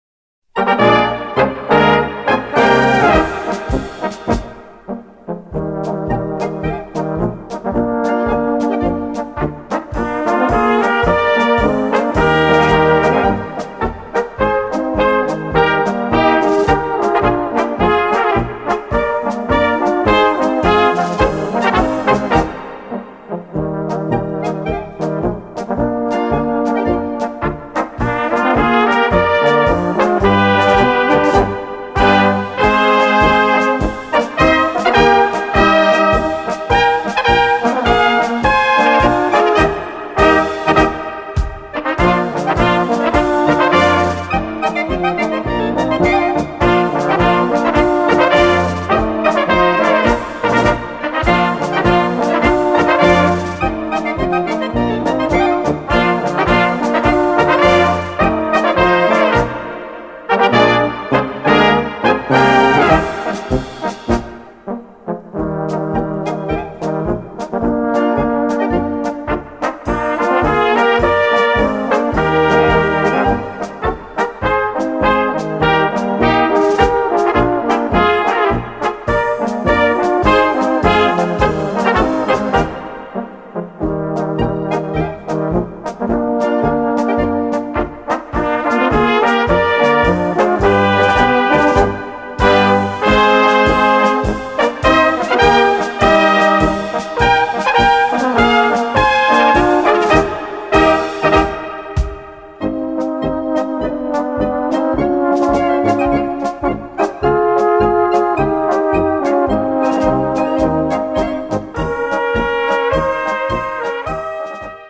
Gattung: Polka
A4 Besetzung: Blasorchester PDF